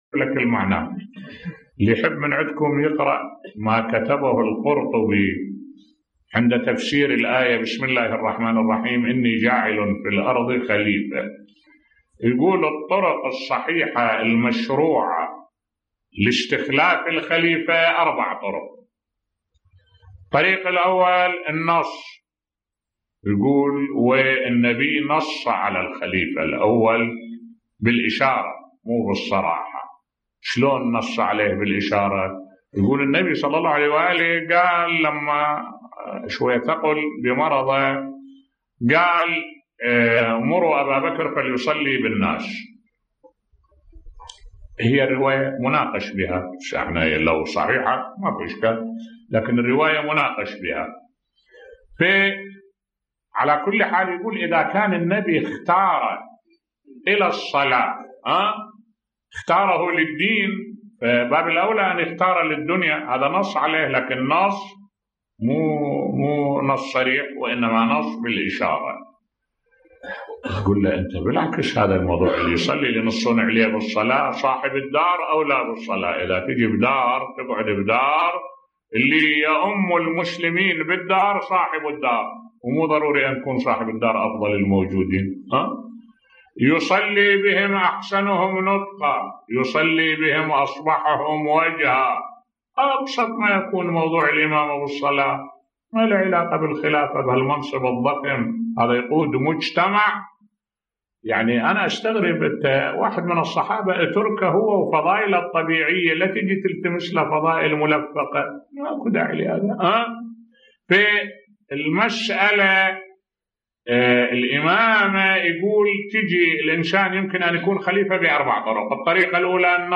ملف صوتی يتعجب من طرق القرطبي في استخلاف الخليفة بصوت الشيخ الدكتور أحمد الوائلي